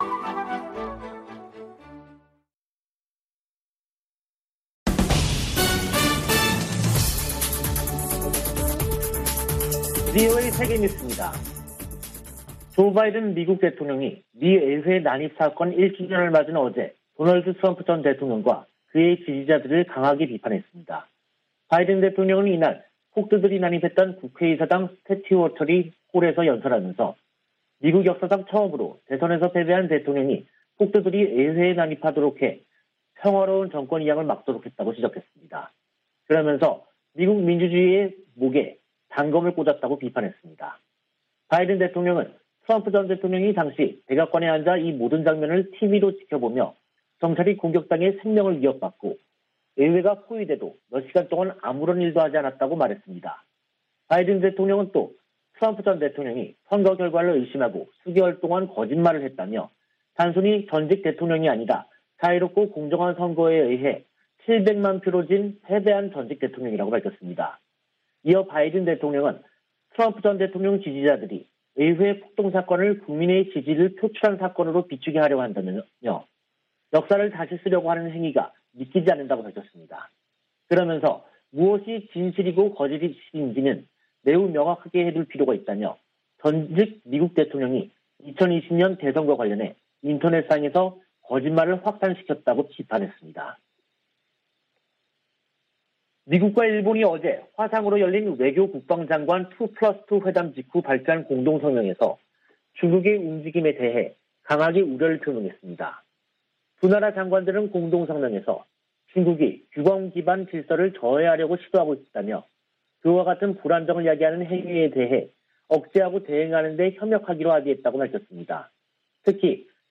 VOA 한국어 간판 뉴스 프로그램 '뉴스 투데이', 2022년 1월 7일 2부 방송입니다. 미국과 일본은 북한의 핵과 미사일 개발에 강한 우려를 표하고 유엔 안보리 결의 준수를 촉구했습니다. 미 국방부는 북한의 미사일 시험발사에 관해 구체적 성격을 평가하고 있다며, 어떤 새로운 능력도 심각하게 받아들인다고 밝혔습니다. 미 국무부는 북한 탄도미사일 관련 안보리 소집 여부에 대해, 동맹과 함께 후속 조치를 논의 중이라고 밝혔습니다.